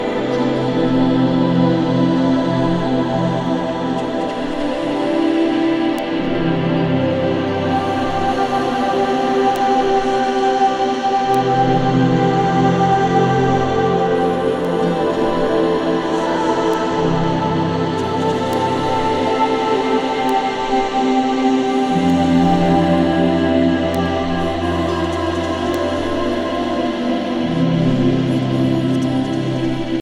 e talvez marcante na pop/rock de 2025